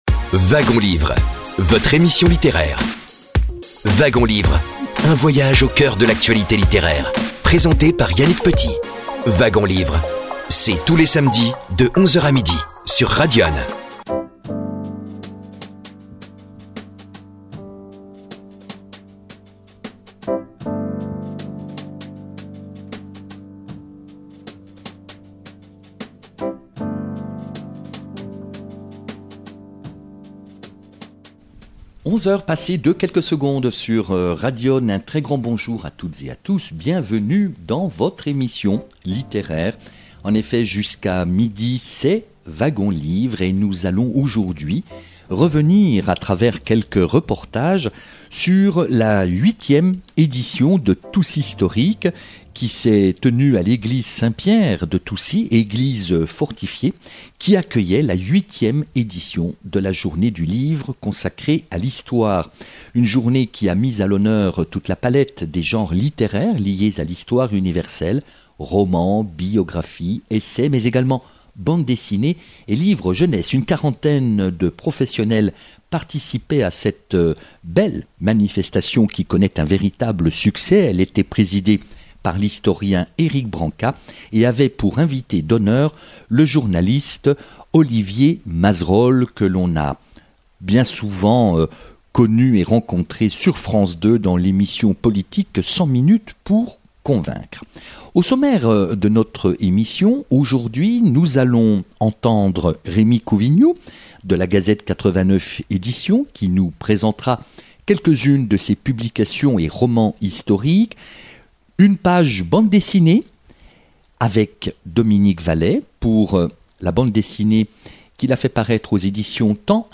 Organisée par l’Association Toucy’Storic, avec le soutien de la librairie Jofac de Toucy et de l’Association du Vieux Toucy, la 8e Journée du Livre d’Histoire a rassemblé une quarantaine d’auteurs dont des bédéistes et des auteurs jeunesse, samedi 23 août 2025. Elle s’est tenue dans le cadre exceptionnel de l’église (fortifiée) Saint-Pierre, à Toucy (89).